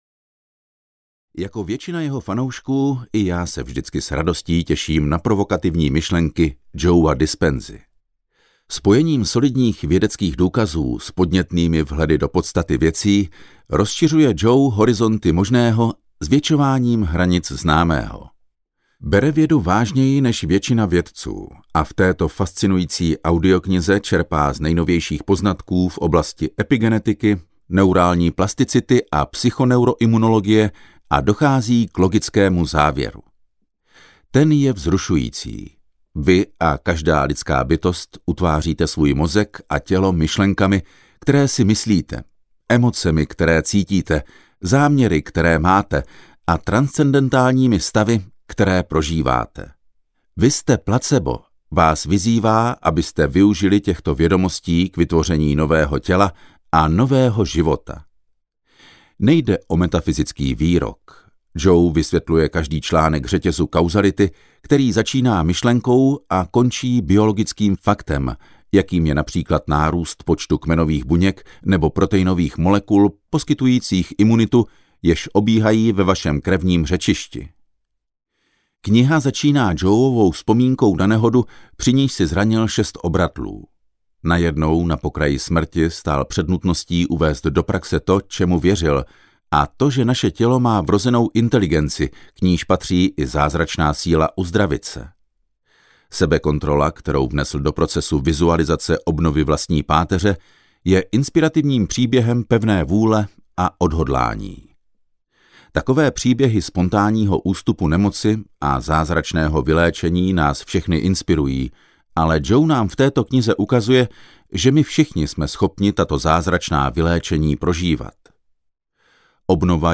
Vy jste PLACEBO audiokniha
Ukázka z knihy